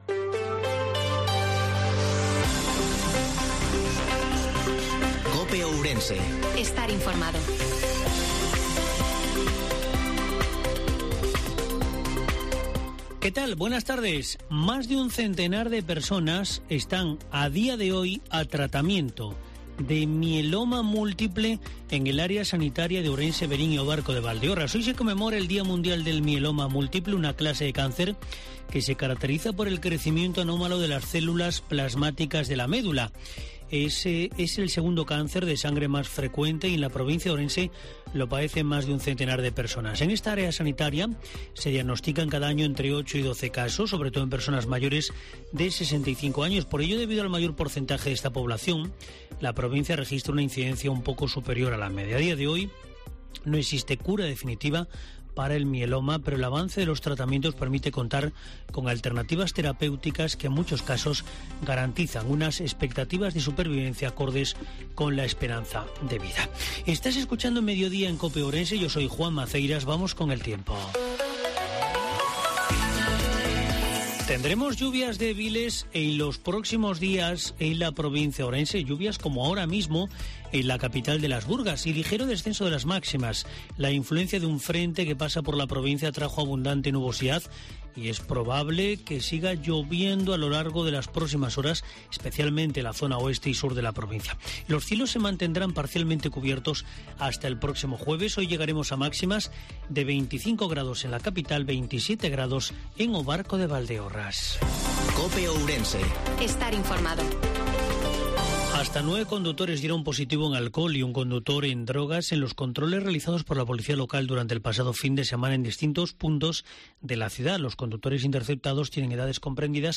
INFORMATIVO MEDIODIA COPE OURENSE-05/09/2022